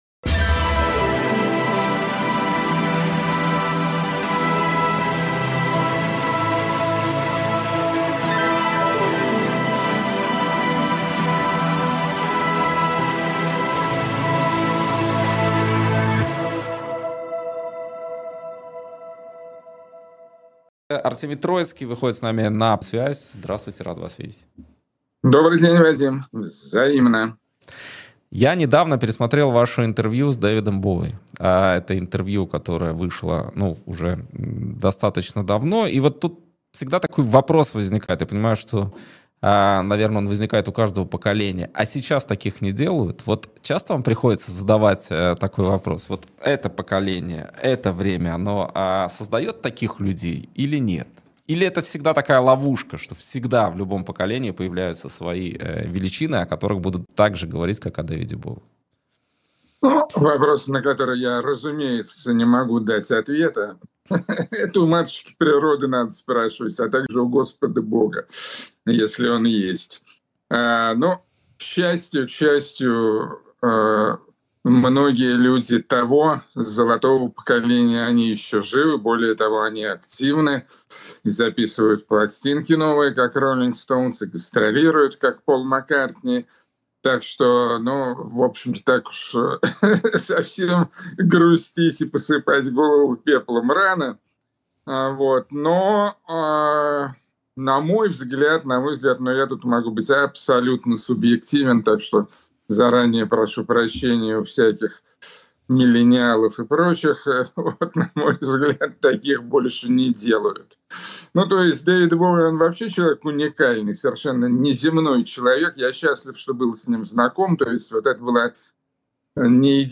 Интервью на канале «И грянул Грэм» (14.10)